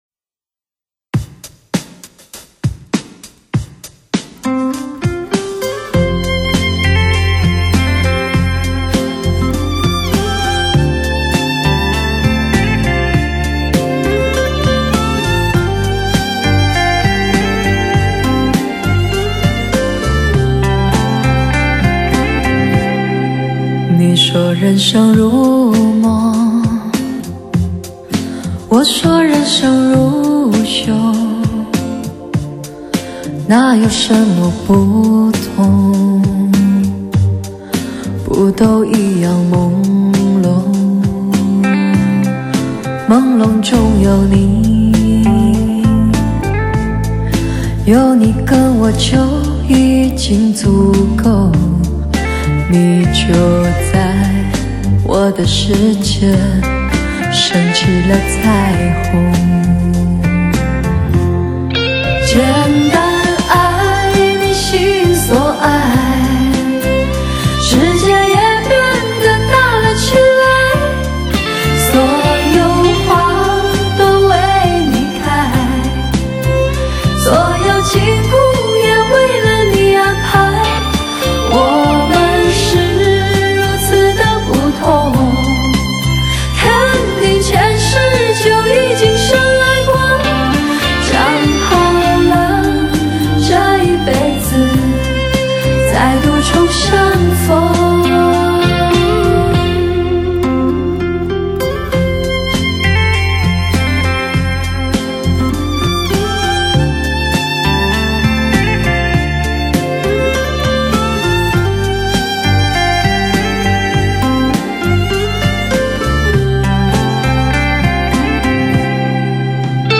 360度环绕车载HIFI天碟
清新音色，磁性迷人歌喉，一声声，一首首，如同一个动人的传说，让人产生遐想和